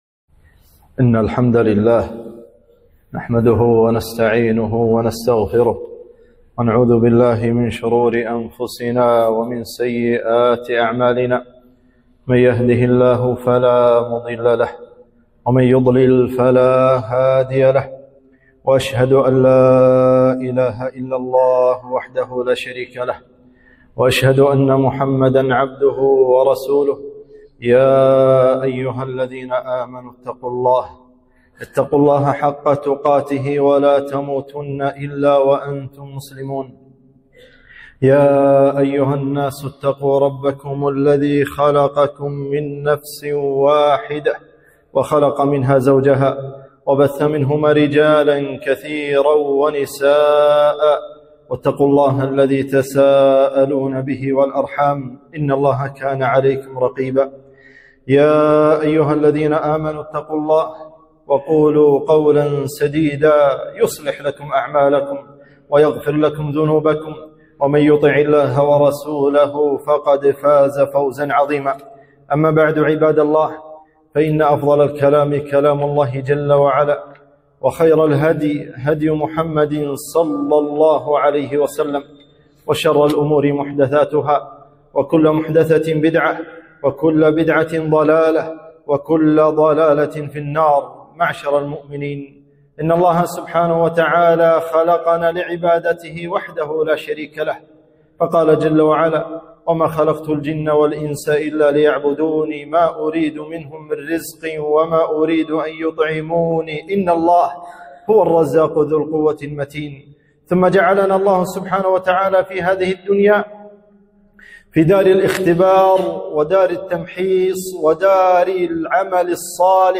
خطبة - الأمنيات المستحيلة